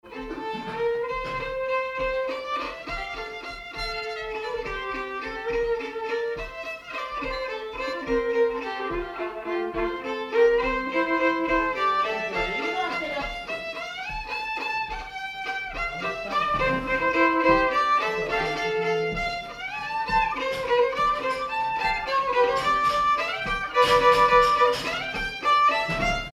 danse : valse
circonstance : bal, dancerie
Pièce musicale inédite